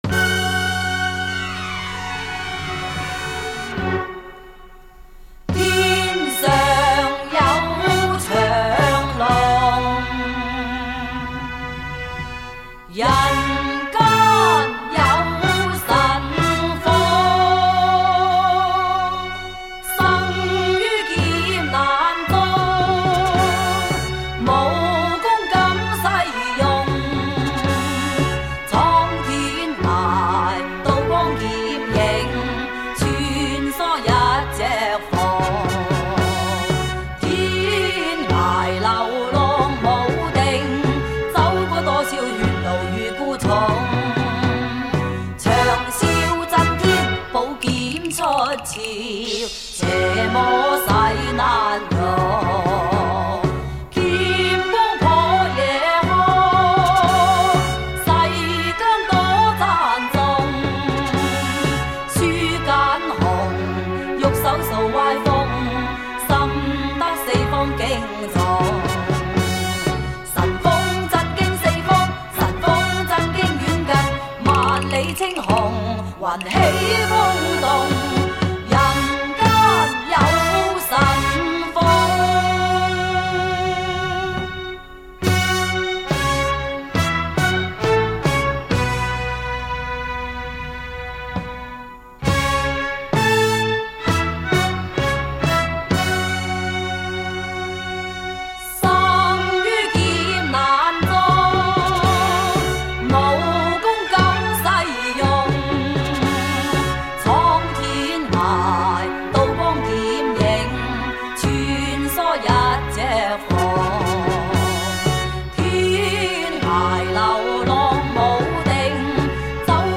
回味从小到大的磁性声音 惟有黑胶原版CD
（粤语）